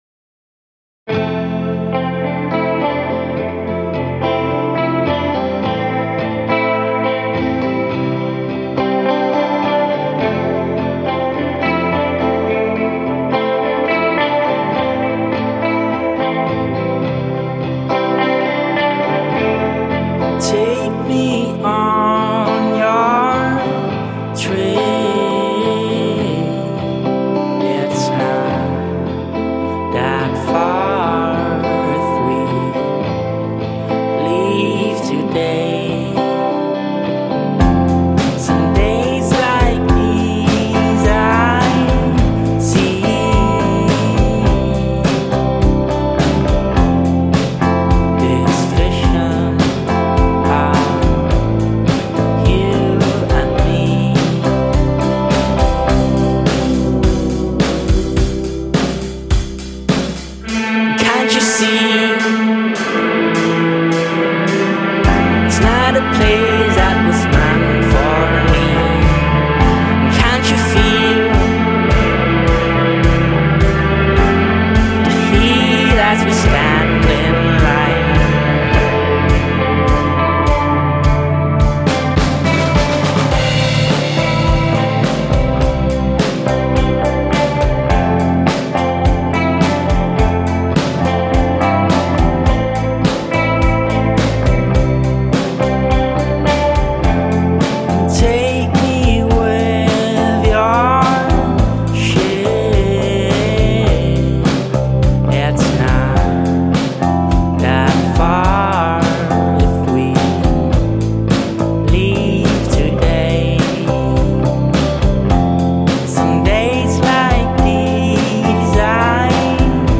four piece indie rock band